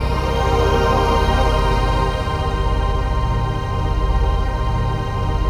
DM PAD2-05.wav